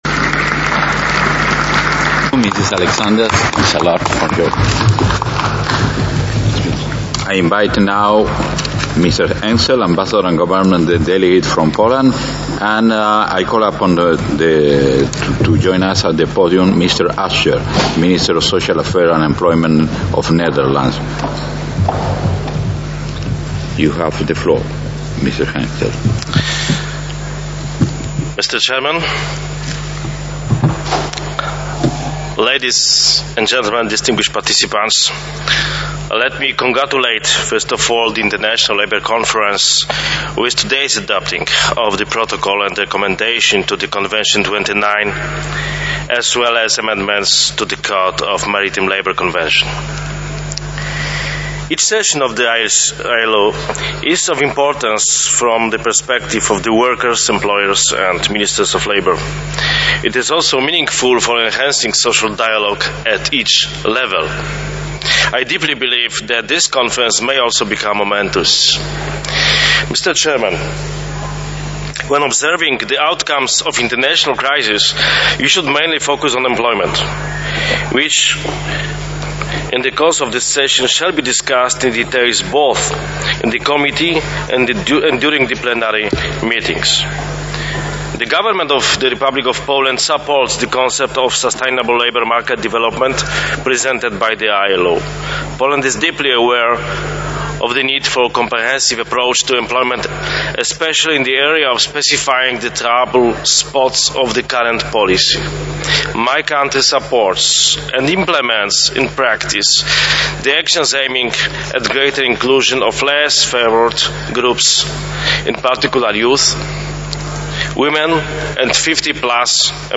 Ambasador Remigiusz Henczel wystąpienie w czasie 103. Sesji MKP